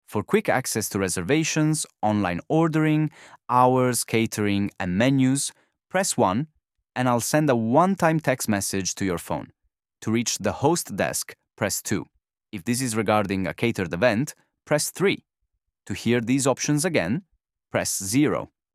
Customize your voice greetings and caller journey with authentic AI voices that match your brand.
Italian Male